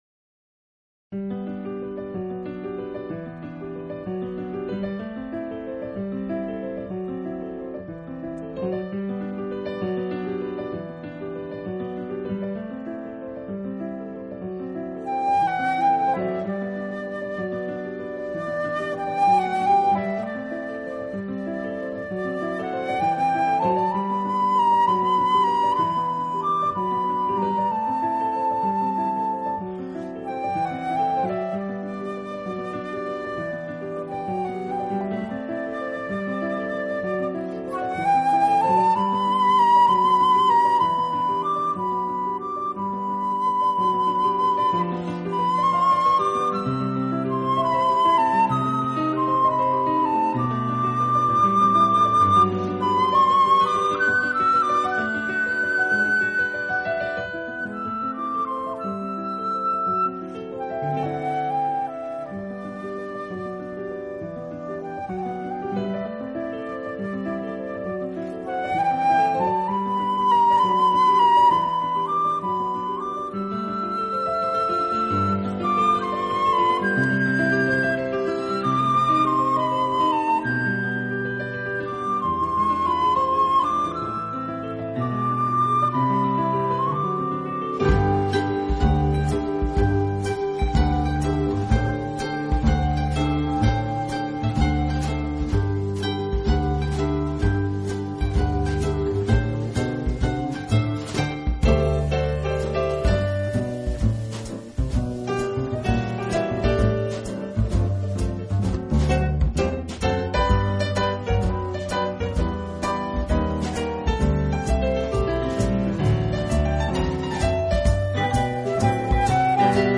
【纯音乐】